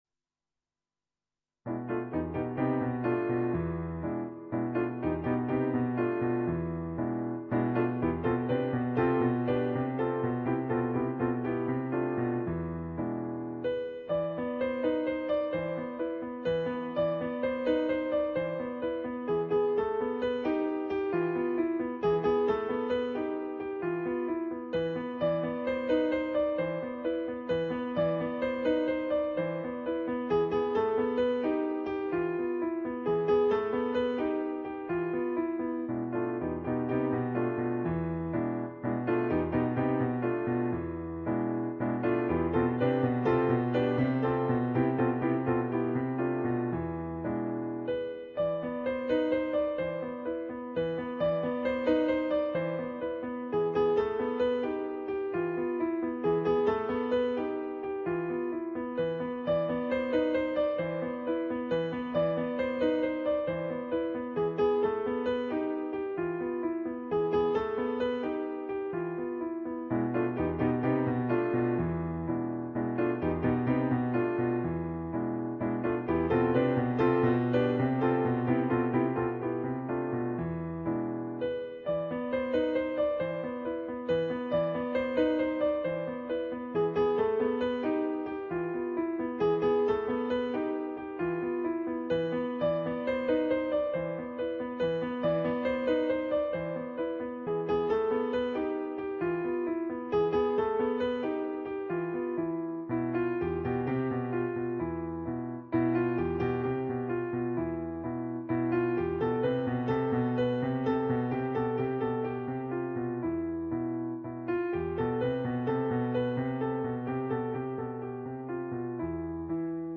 for Solo Piano
on Yamaha digital pianos.